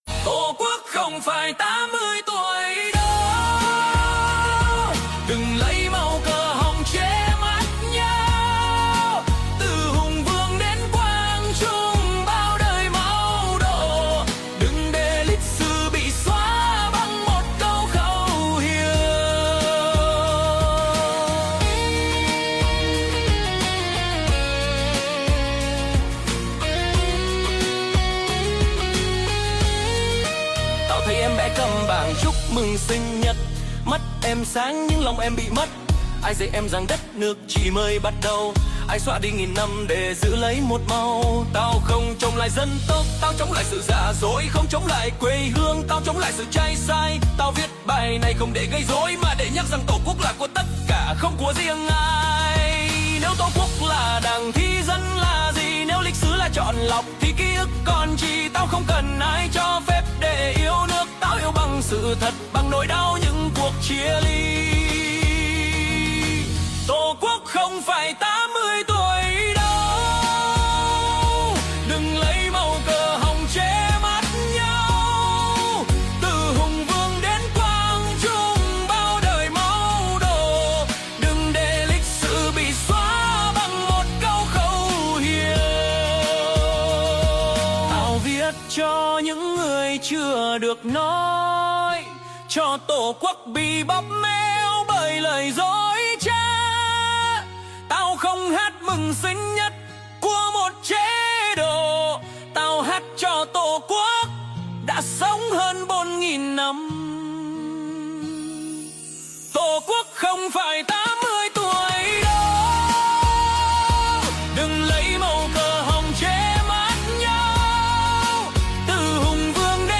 Chủ đề: nhạc trẻ đấu tranh
Trình bày: ban hợp xướng tuổi trẻ vn
Bài hát thuộc thể loại RAP [Rhythm And Poetry], thuộc văn hoá "hip hop" nổi bật với việc đọc lời rất nhanh theo nhịp điệu thay vì hát hò như trong nhạc truyền thống. Nhạc gồm điệp khúc, phiên khúc, và kết.
ban nhạc trẻ tự do hợp ca: (MP3; size: 2.19MB)